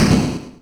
snd_impact_ch1.wav